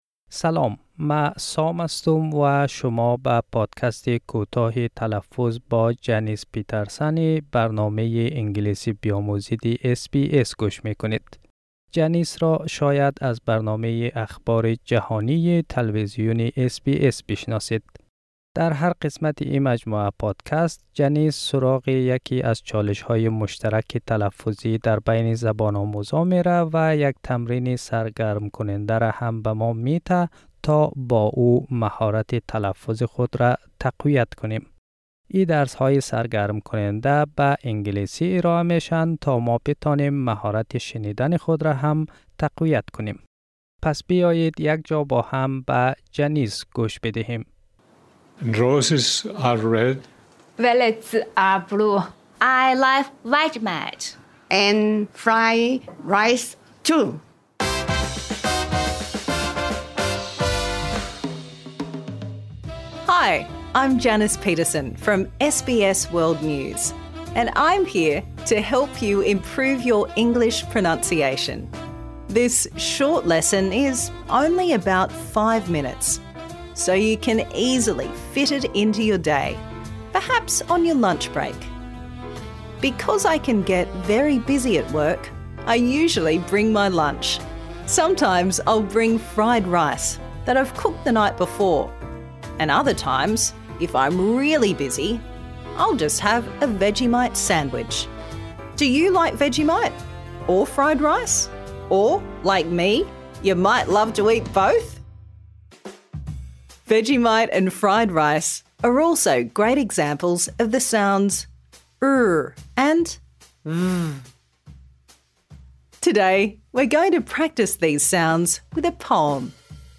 در این قسمت، نحوه تلفظ /r/ و /v/ را یاد می‌گیرید.